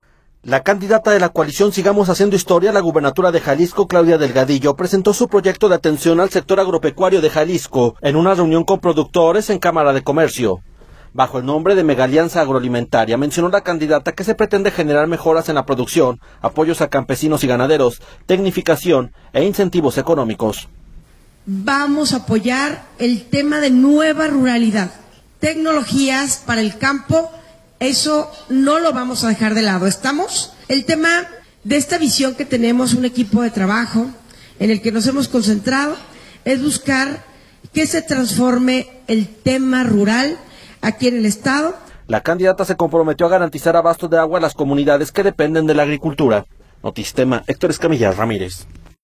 audio La candidata a de la coalición Sigamos haciendo historia a la gubernatura de Jalisco, Claudia Delgadillo, presentó su proyecto de atención al sector agropecuario de Jalisco, en una reunión con productores en Cámara de Comercio.